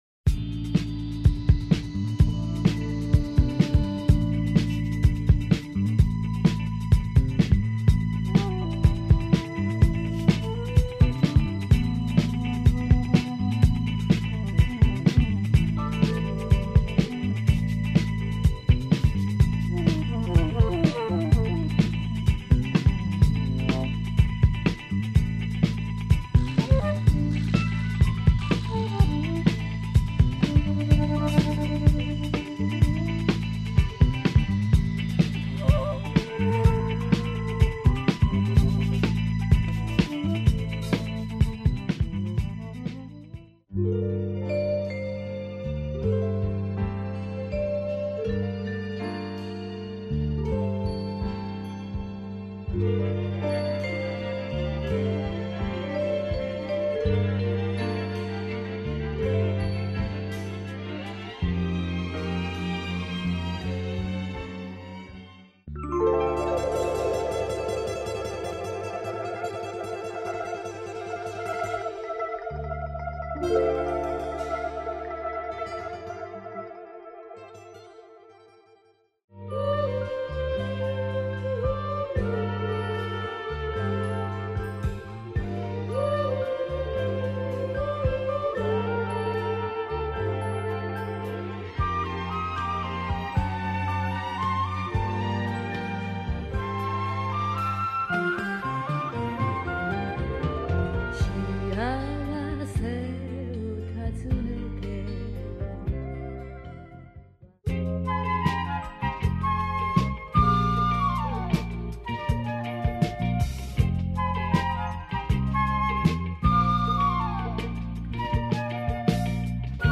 Funk & beats !
Soundtracks